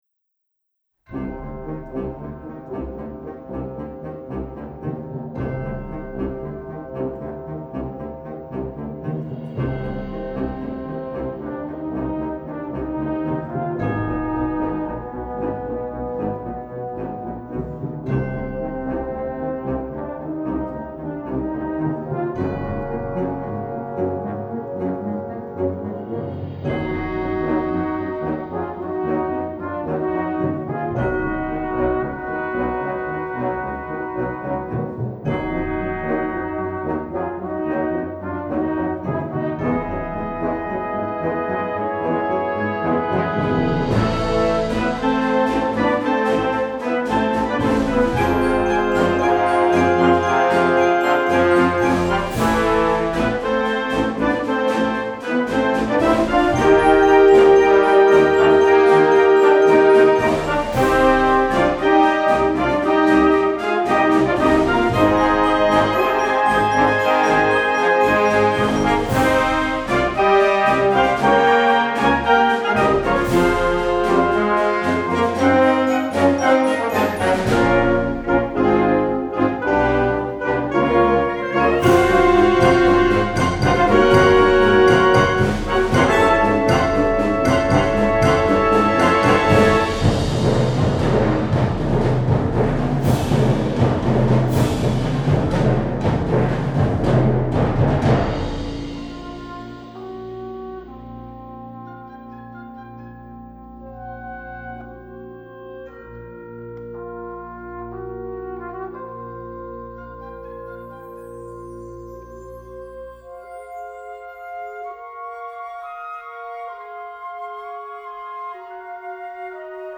Gattung: Konzertwerk
A4 Besetzung: Blasorchester PDF